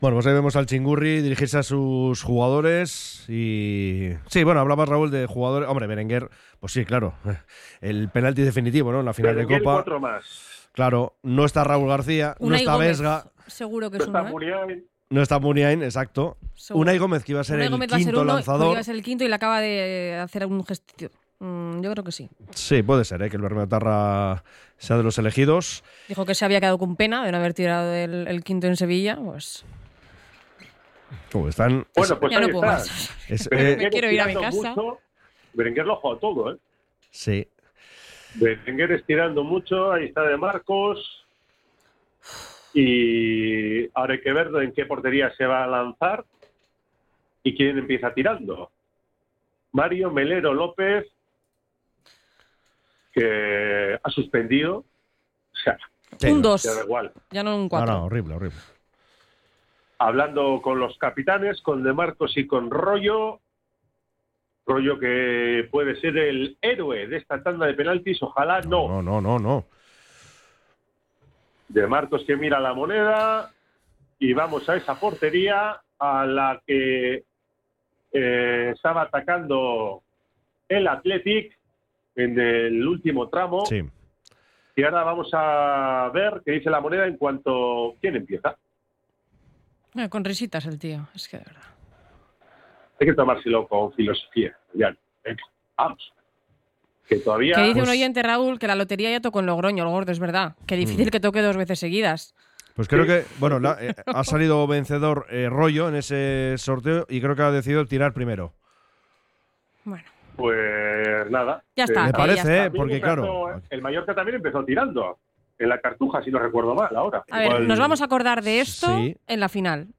⚽ ¡Así hemos vivido la tanda de penaltis en Las Gaunas! | UD Logroñés 0-0 Athletic Club